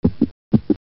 latido
latido.mp3